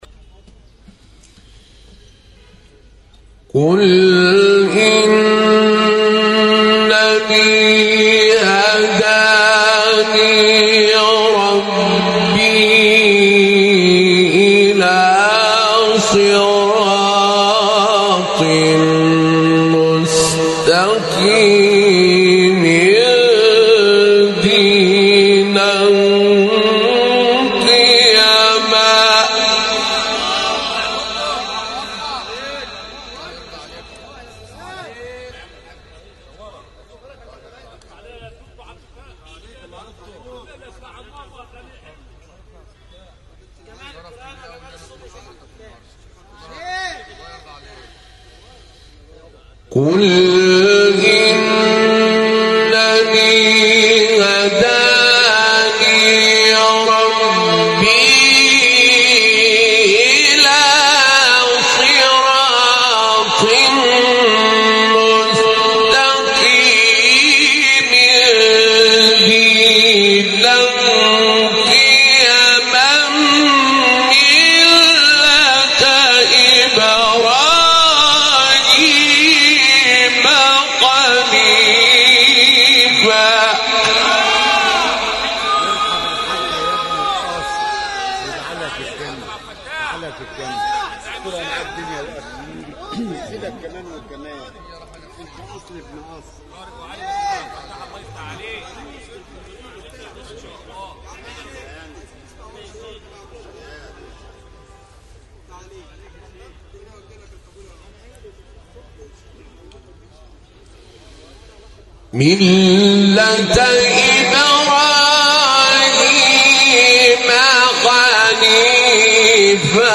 تلاوت شاهکار و فوق العاده سوره انعام استاد طاروطی | نغمات قرآن
مقام : رست اختلاف قرائت : ورش از نافع مدنی